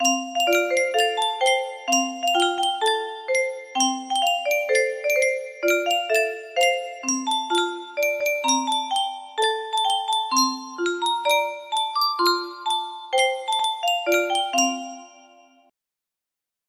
Yunsheng Music Box - Unknown Tune 1088 music box melody
Full range 60